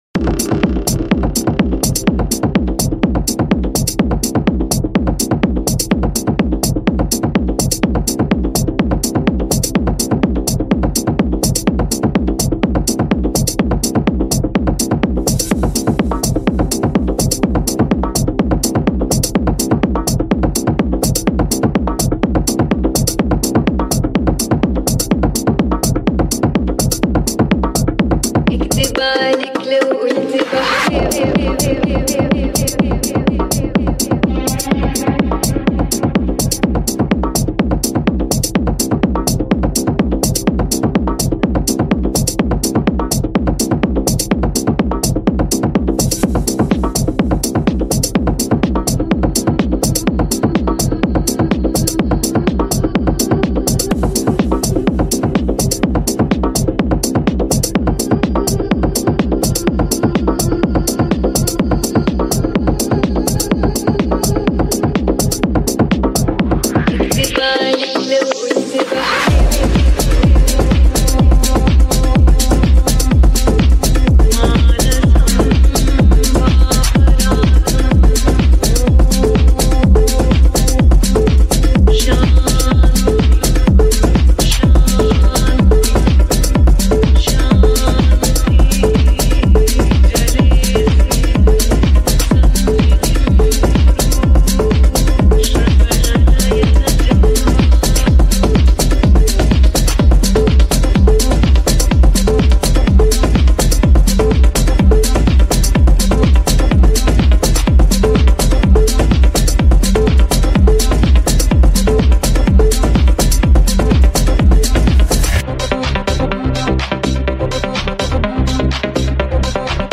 Genre - TECH HOUSE
BPM - 125